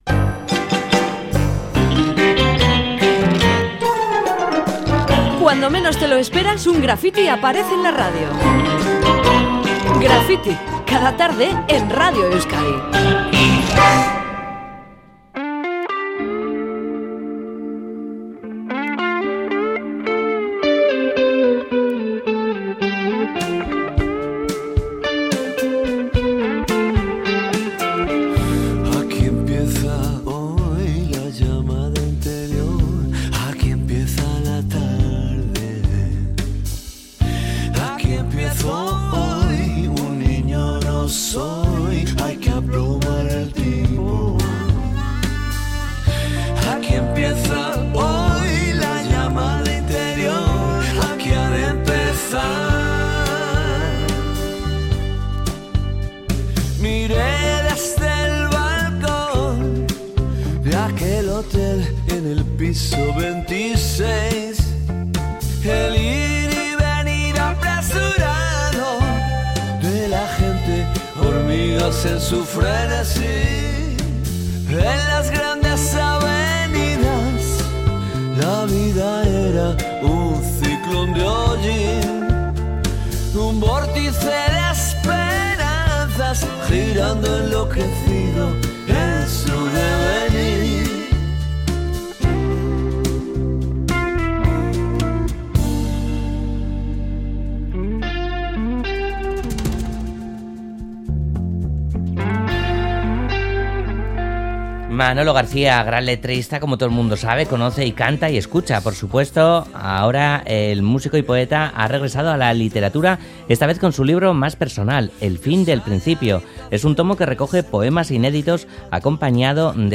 Audio: Hablamos con Manolo García sobre su nuevo poemario, en el que trata temas como la destrucción del medio ambiente o la adicción a las nuevas tecnologías.